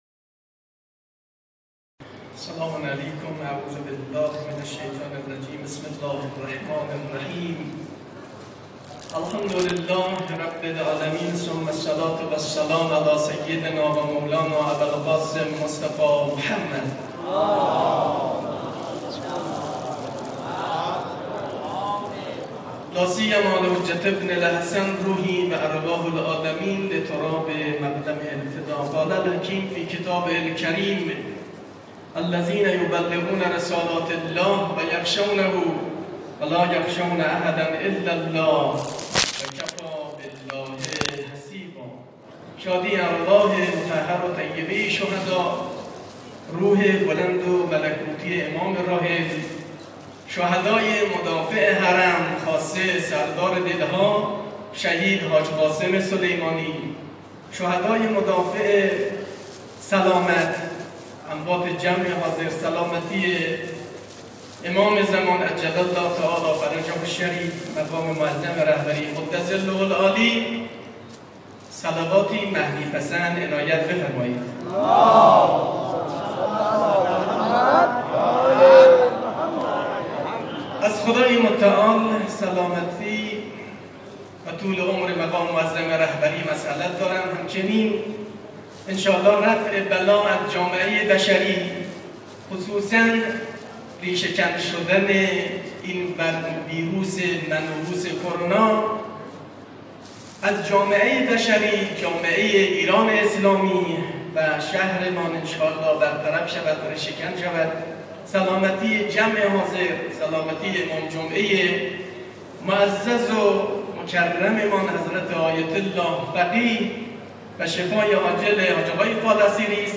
سخنرانی
بمناسبت سالروز تاسیس سازمان تبلیغات اسلامی